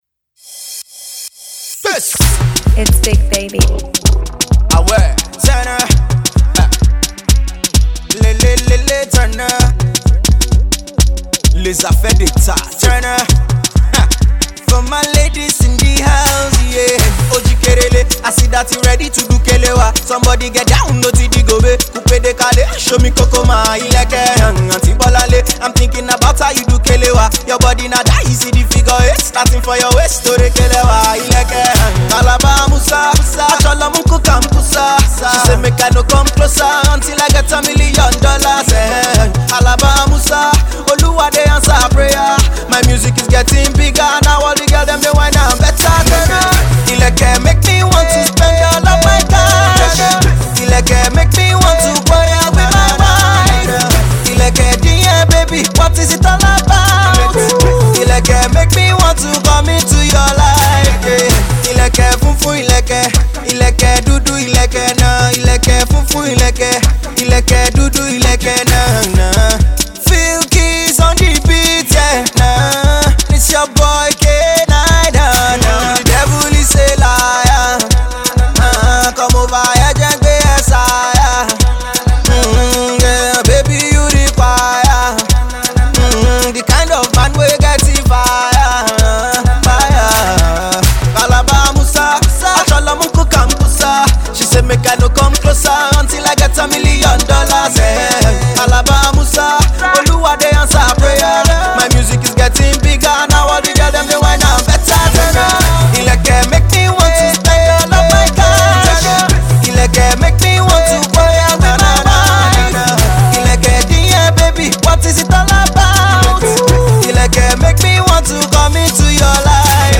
I foresee a lot of shoki lovers moving and dancing to this.